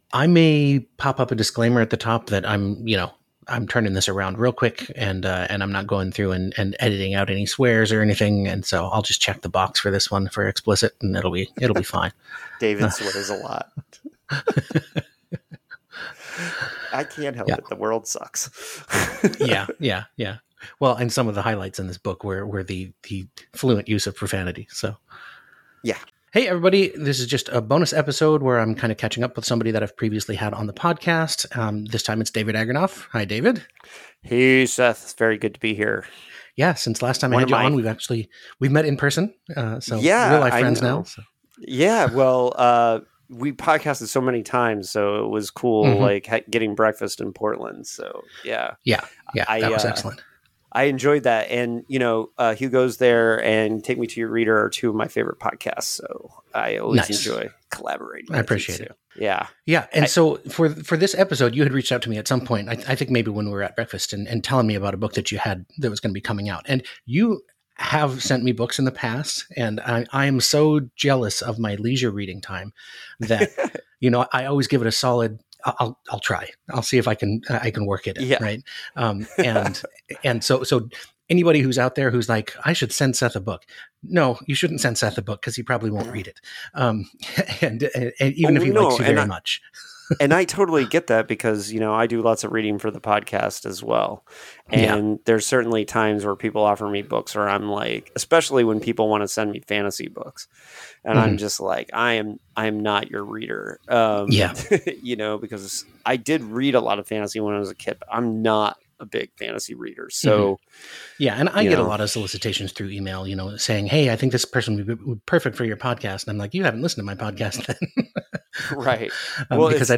interview with the author
This is about as raw as you’ll get a Hugos There episode, so it’s marked Explicit for language.)